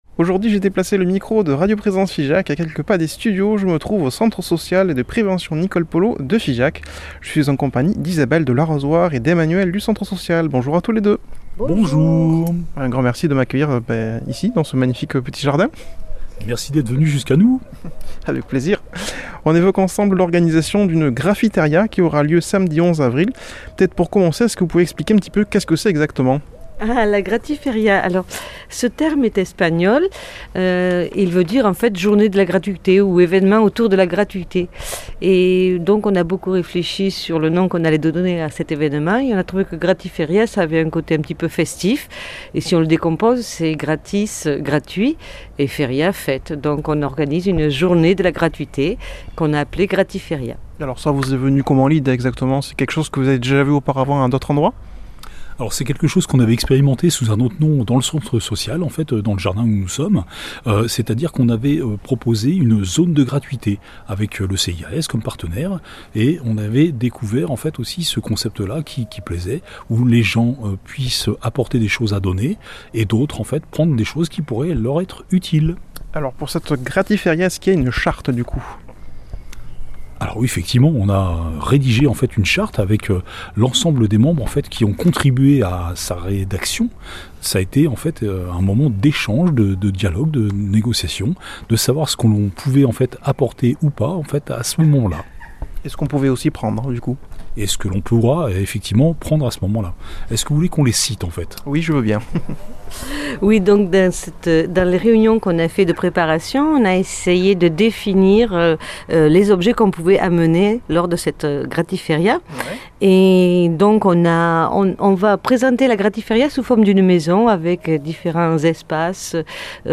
a déplacé le micro de Radio Présence Figeac à quelques pas des studios